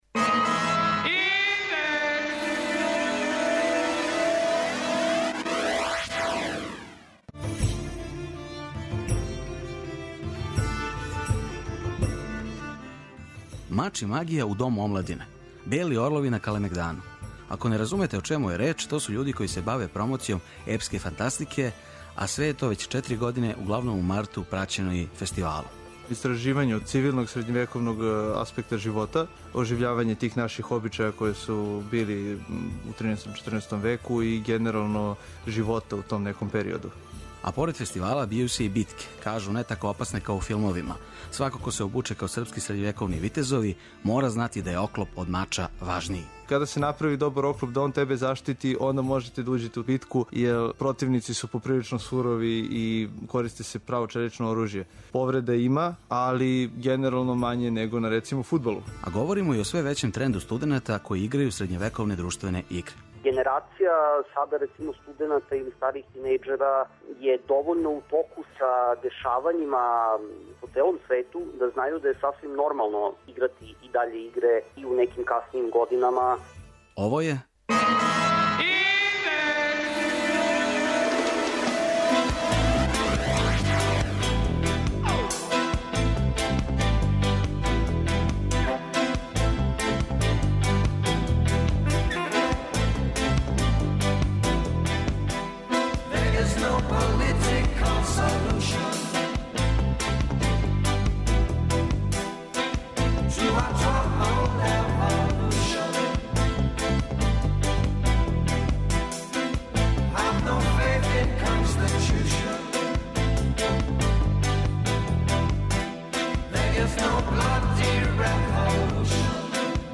''Индекс 202'' је динамична студентска емисија коју реализују најмлађи новинари Двестадвојке.